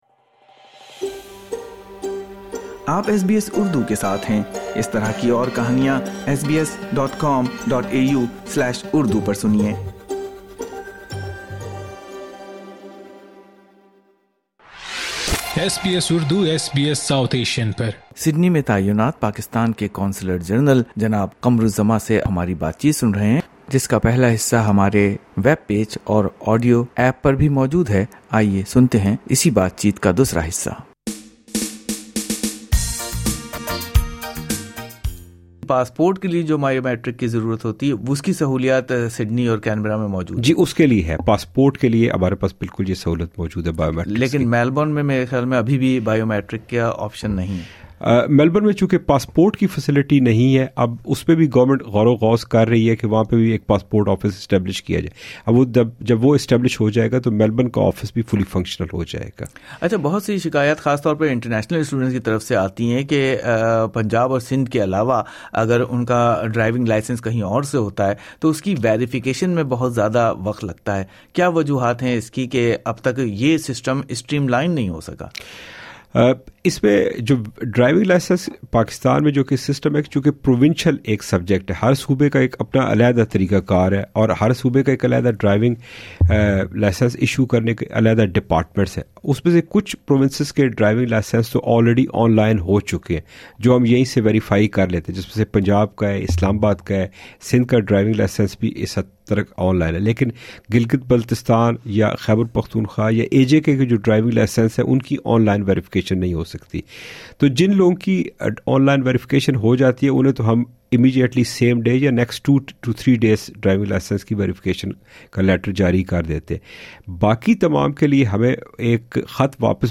Discover which Pakistani consular services can be accessed digitally and which require a visit to the diplomatic mission. General Qamar-ul-Zaman revealed these details in an interview.